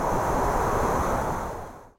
겨울 들판을 스치는 바람 소리였다.
겨울바람소리.mp3